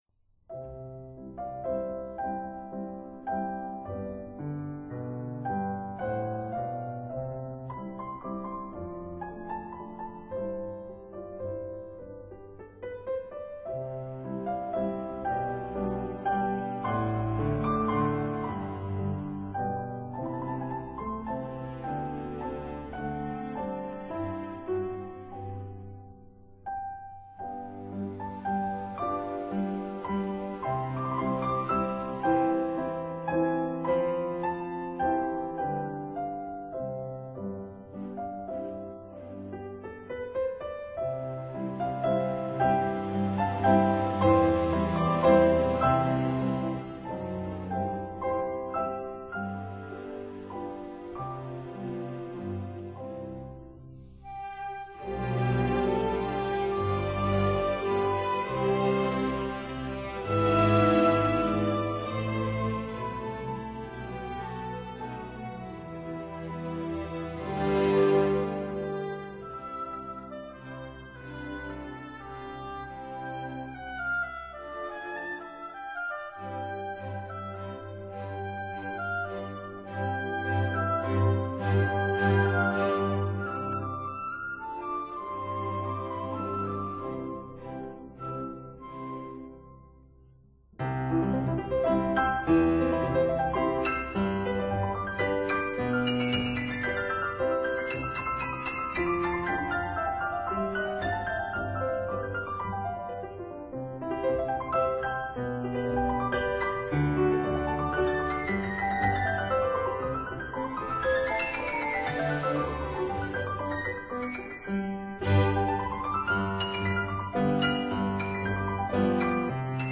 piano & conductor